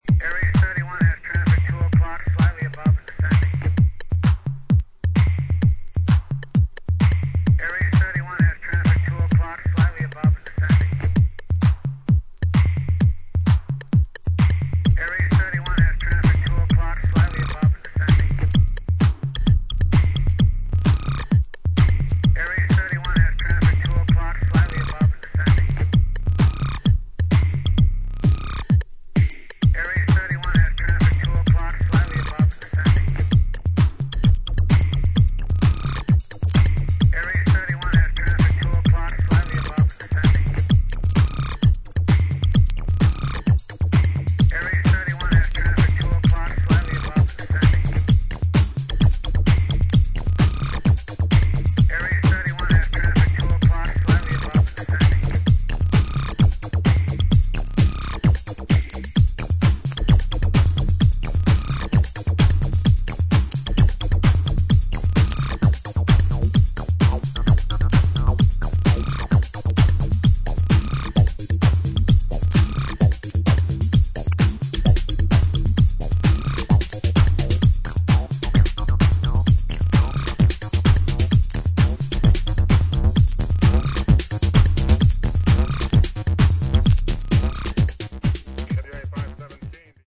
チリノイズあり。